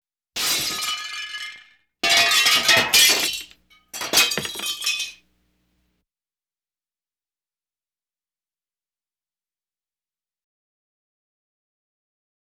Window Glass Breaking Sound Effect
Download a high-quality window glass breaking sound effect.
window-glass-breaking.wav